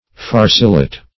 Farcilite \Far"ci*lite\, n.
farcilite.mp3